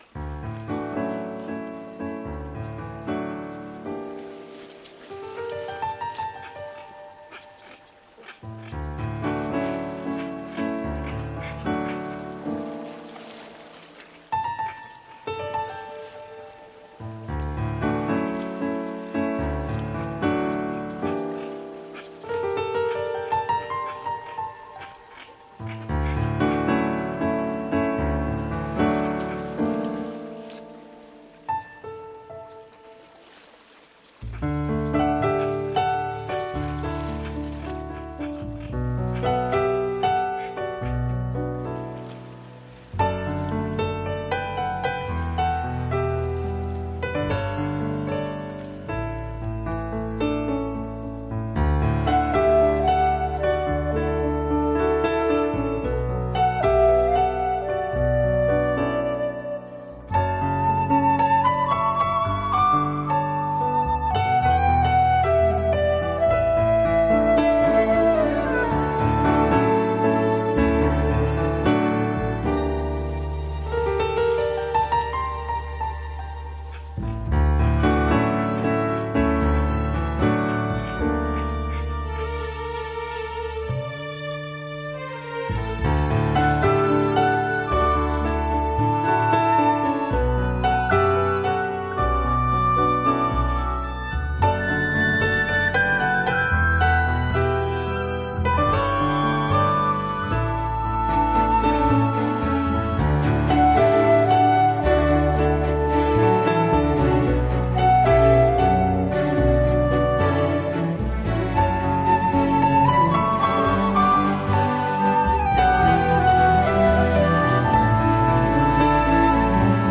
加入大量各类形电子乐器，如手风琴，口琴，敲击，笛子，管乐等等。这张专辑风格多变，每首曲目有很有节奏。